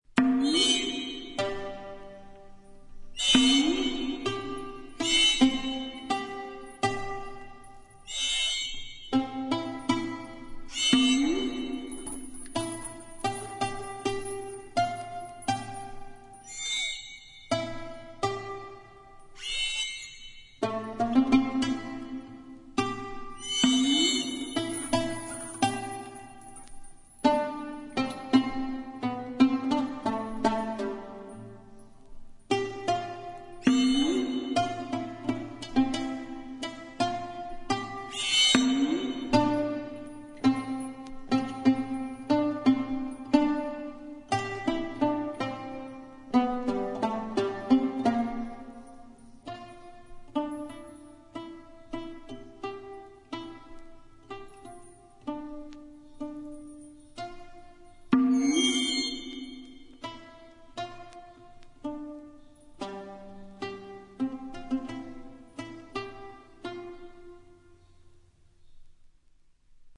細部に至るまでをも表現するべく、当時の楽器36種類を復元・制作。